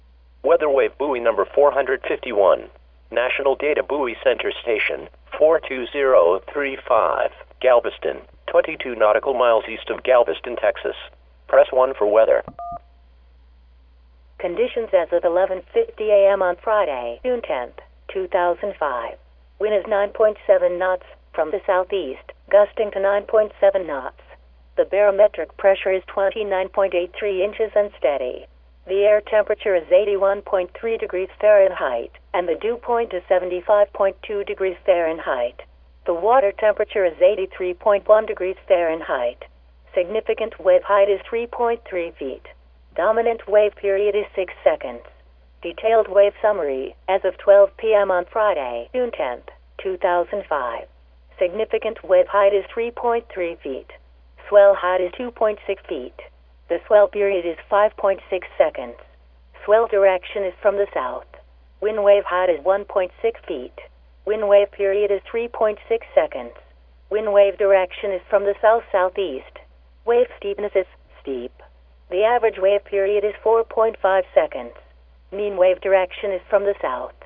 sample_buoy_report.mp3